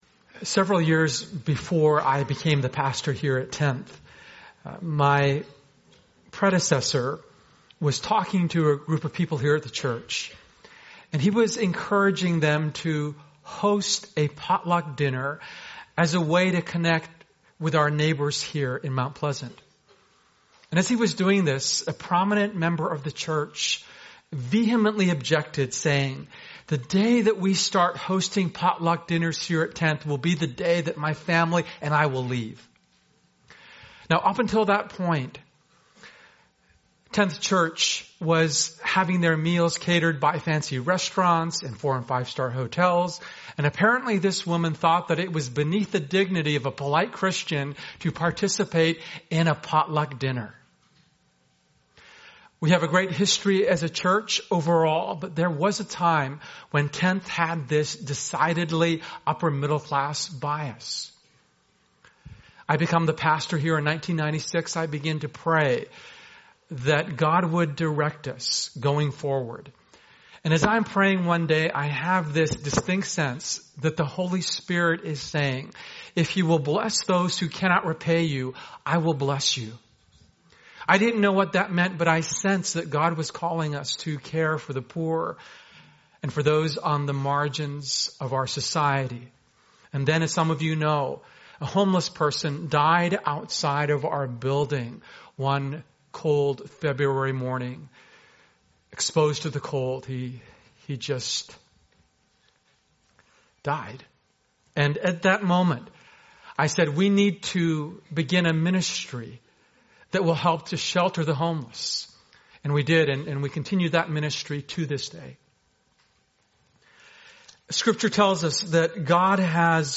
public ios_share Tenth Church Sermons chevron_right God's Love for the Unborn Jan 4, 2015 A sermon on the church’s call to bless vulnerable children and a new focus on fullness of life for every child. Discussion of abortion statistics, fetal development facts, and early Christian witness against abortion. Pastoral care for those who regret abortion, encouragement toward adoption, sexual purity, and practical ways to advocate and support pregnant people. 00:00 forum Ask episode play_arrow Play